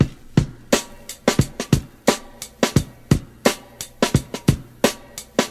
87 Bpm Breakbeat C# Key.wav
Free drum beat - kick tuned to the C# note. Loudest frequency: 3218Hz
87-bpm-breakbeat-c-sharp-key-Rau.ogg